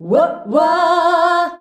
UAH-UAAH F.wav